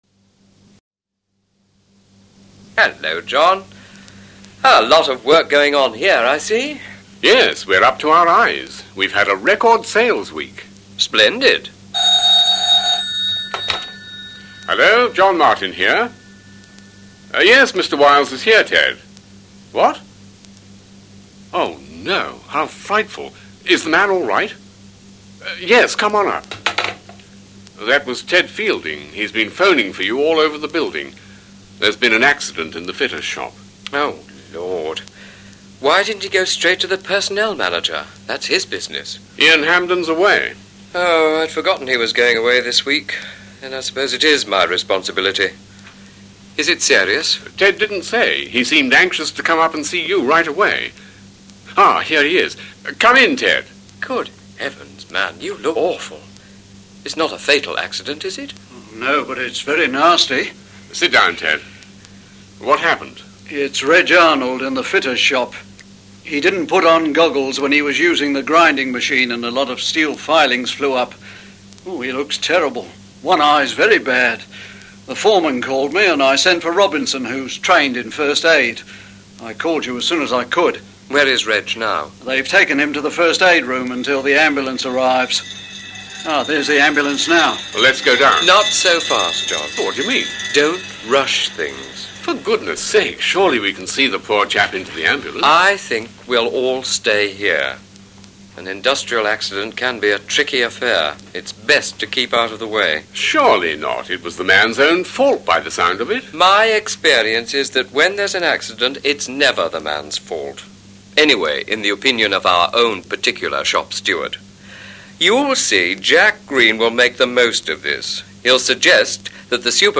conversation04.mp3